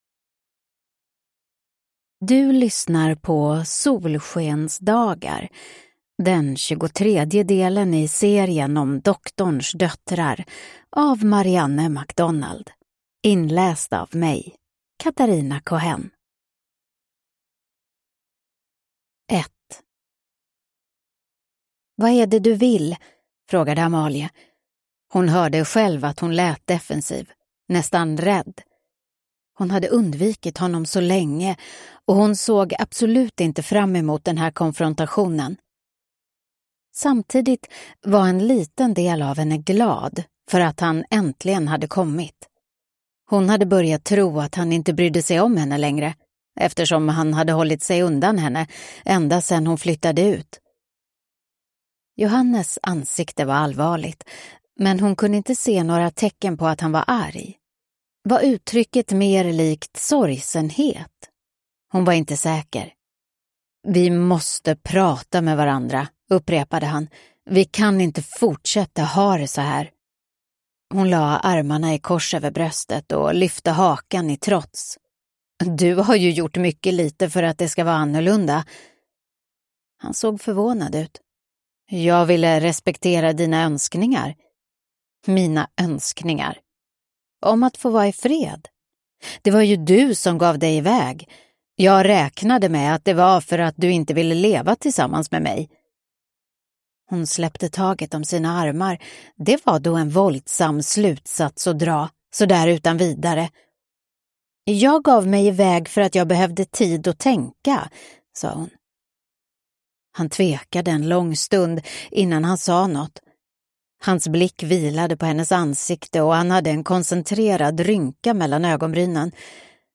Solskensdagar (ljudbok) av Marianne MacDonald